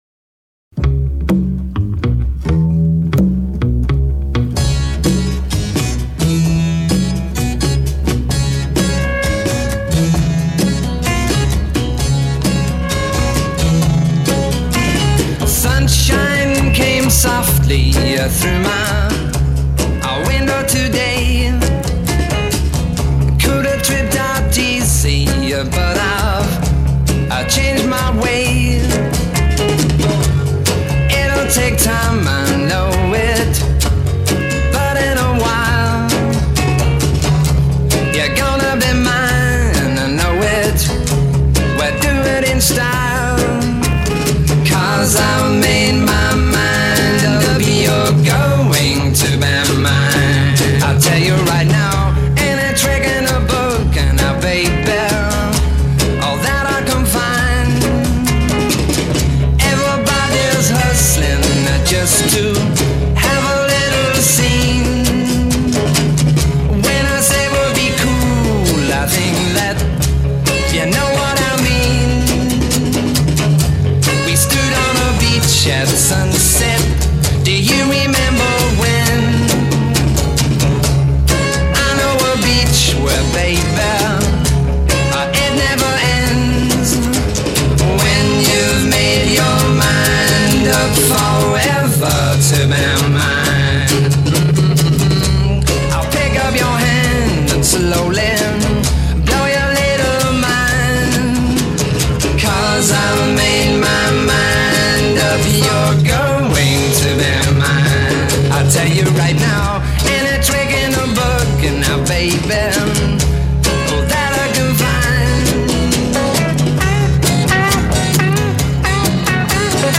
Это была одна из первых психоделических записей.